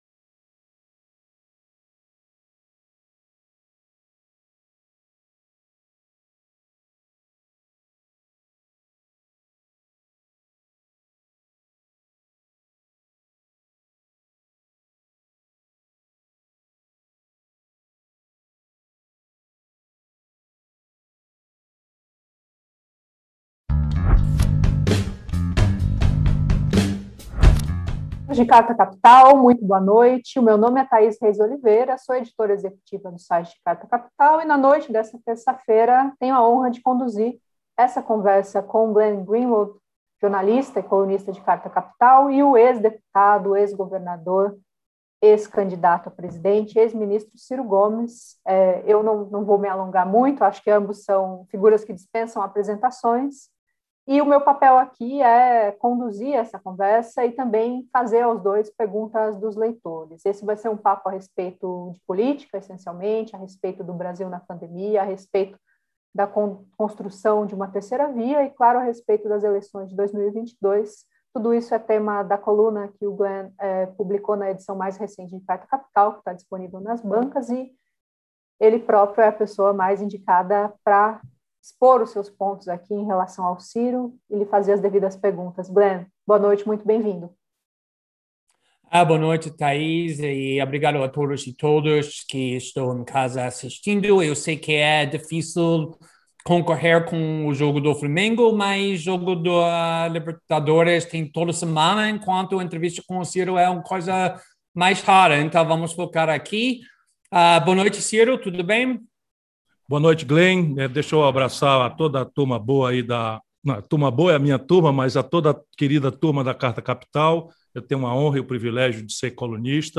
Este podcast reúne todas as entrevistas, palestras e manifestações políticas de Ciro Gomes em áudio. Em defesa de um novo projeto nacional de desenvolvimento!!!